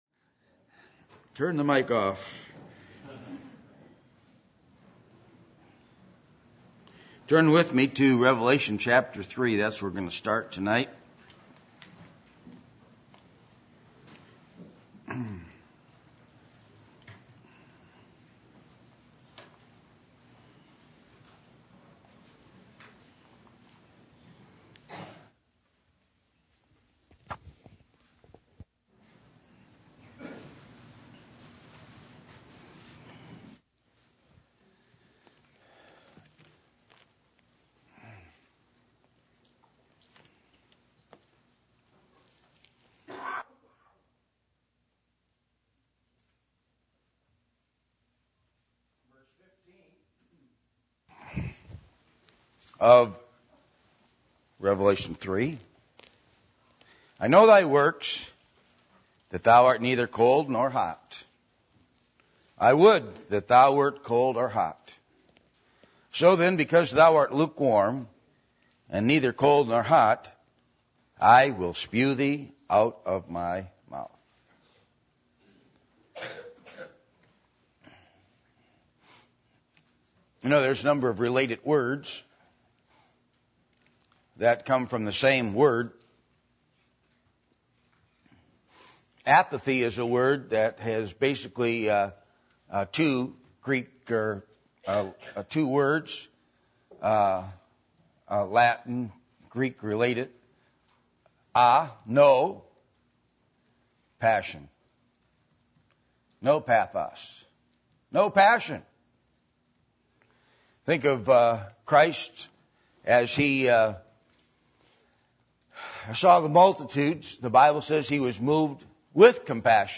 Passage: Revelation 3:15-16 Service Type: Sunday Evening %todo_render% « The Eternal City